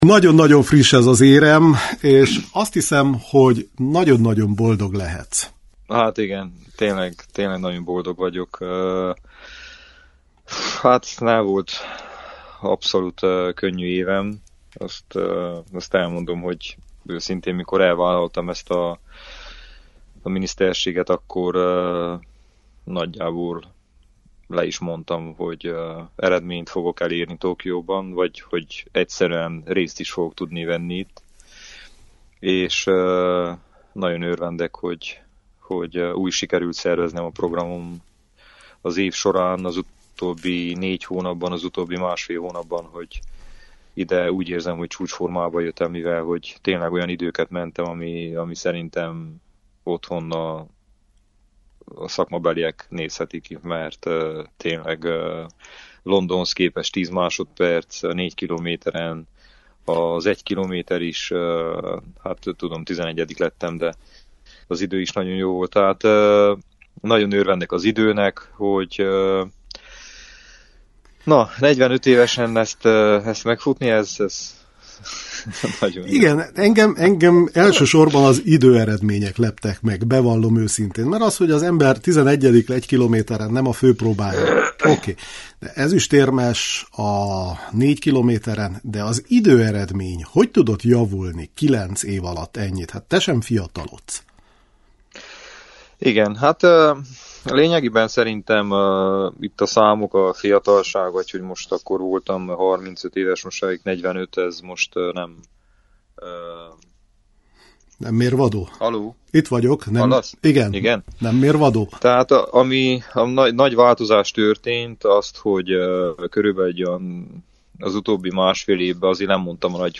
A csíkszeredai kerékpározót az ezüstérem átvétele után értük utol a japán fővárosban:
Novák Ede ezüstérmes Tokióban – interjú!